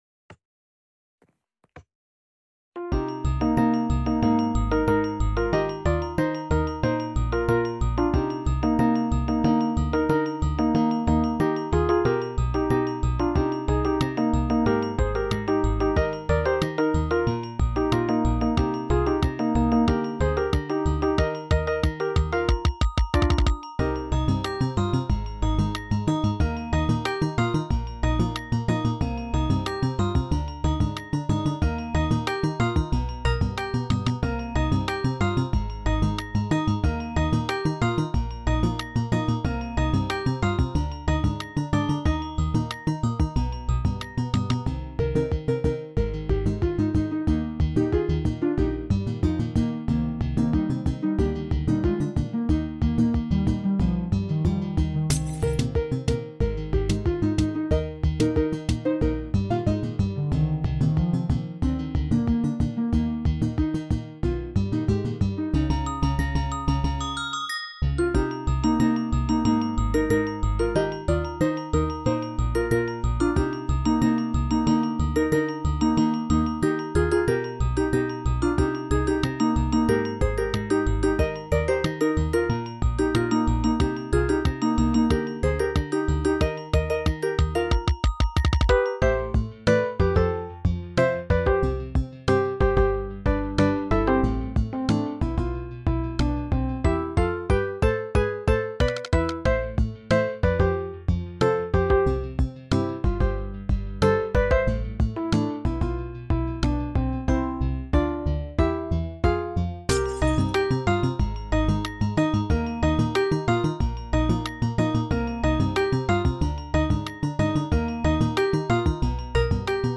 with Minecraft Noteblocks!!